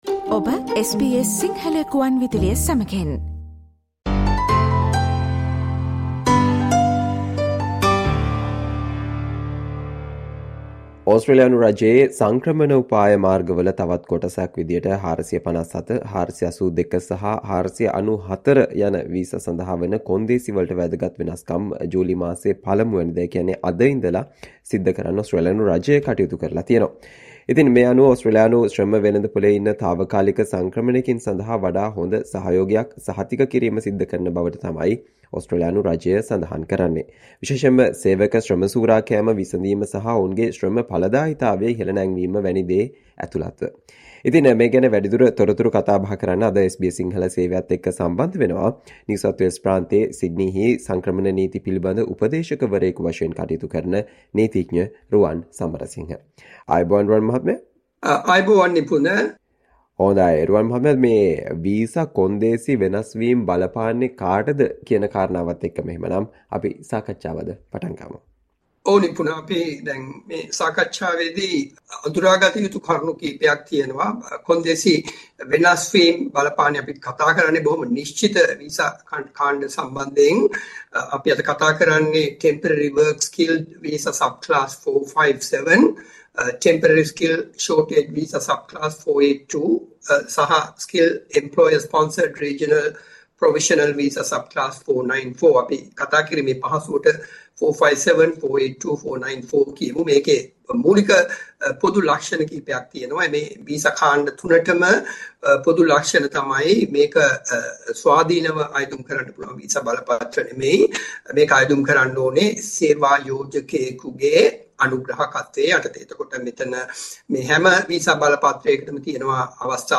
SBS Sinhala discussion on changes to 457, 482 and 494 visa conditions from 1 July as part of the Australian Government's immigration strategy